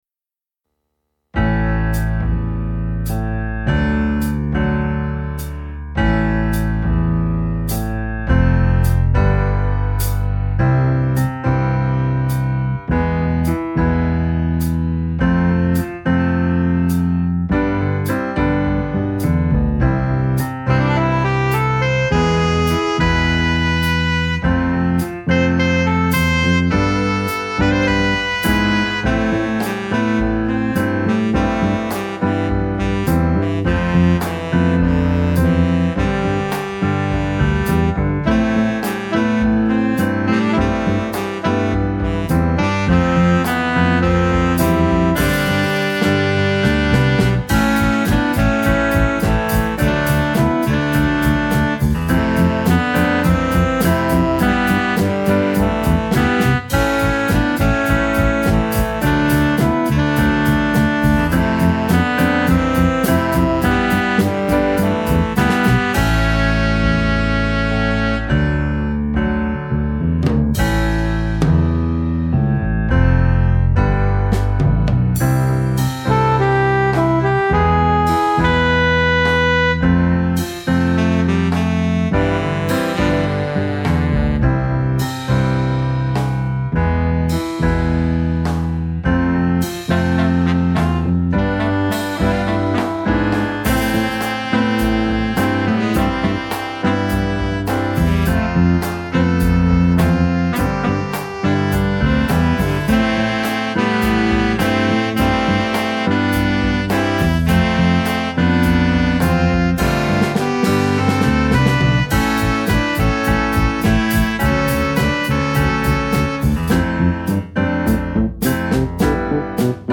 minus Instrument 1